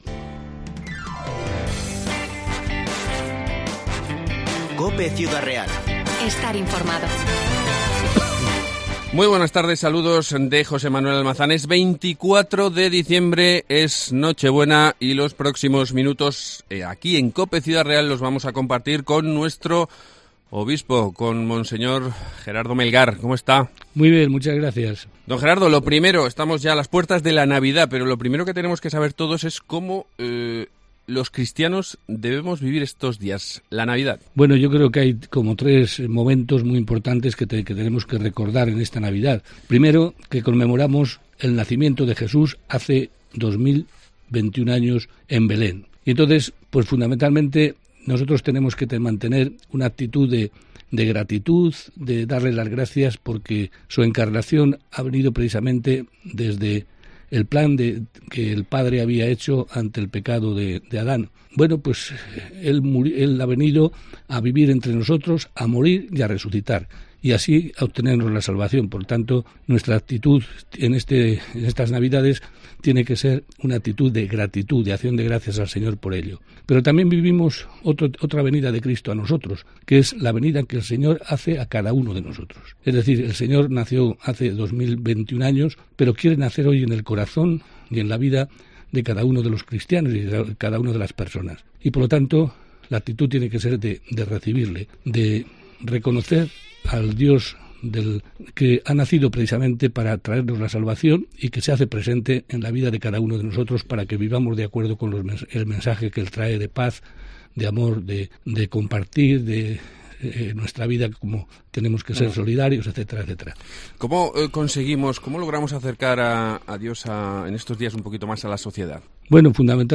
Entrevista al Obispo de Ciudad Real, Mons. Gerardo Melgar